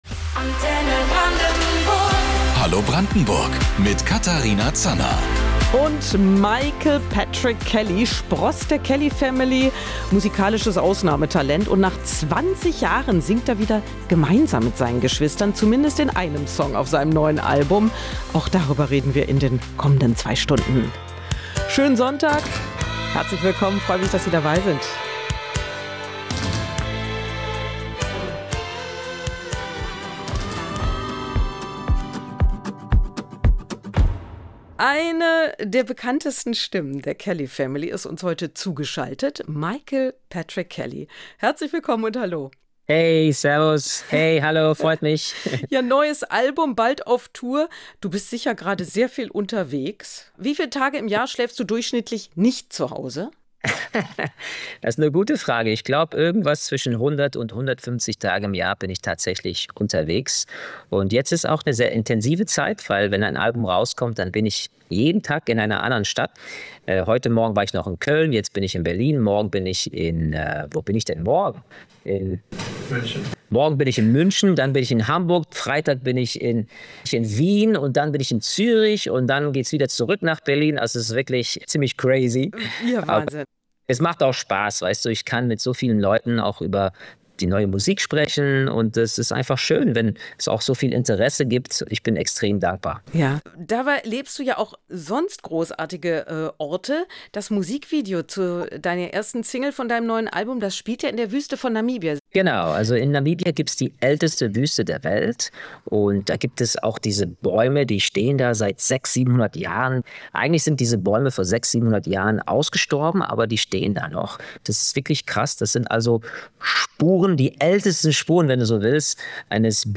Am Sonntag war er unser Gast.